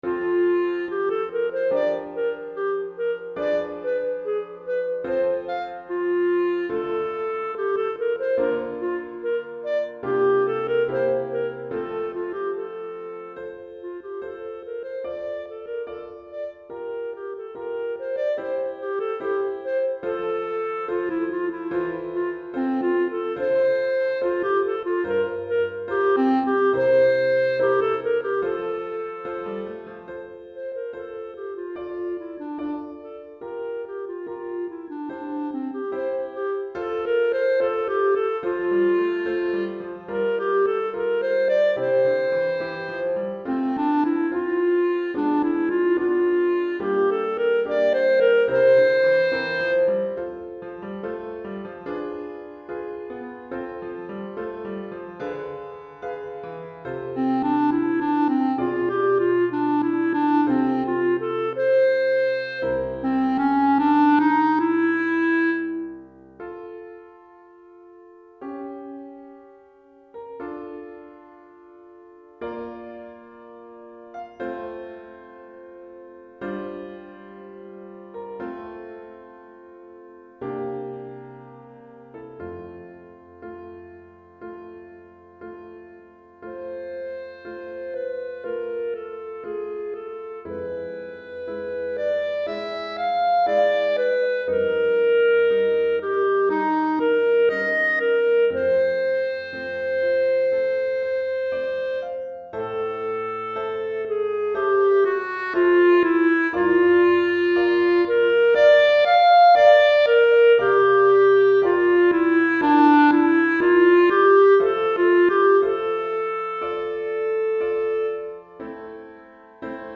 Pour clarinette et piano DEGRE CYCLE 2